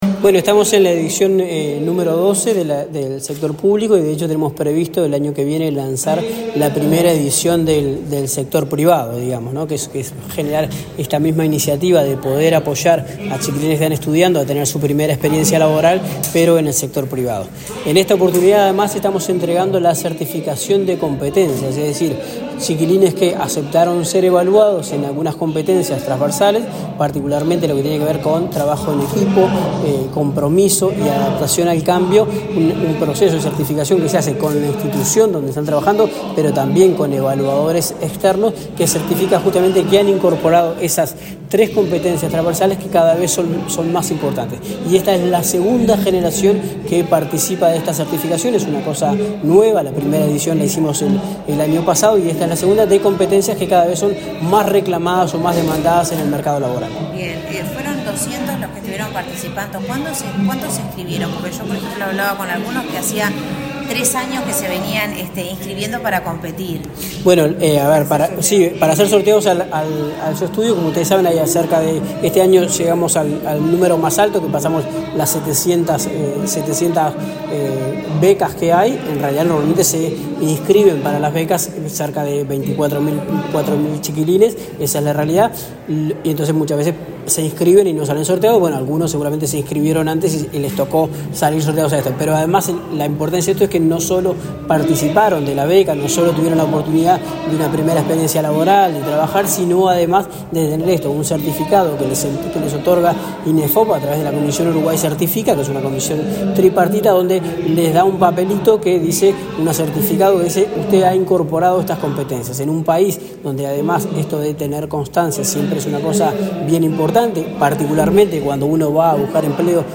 Declaraciones del director nacional de Empleo, Daniel Pérez
El director nacional de Empleo, Daniel Pérez, dialogó con la prensa, luego de participar, este jueves 9 en Montevideo, de la entrega de diplomas a